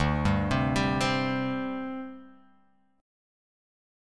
D7sus4 chord